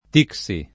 [ t & k S i ; lig. t &X i ]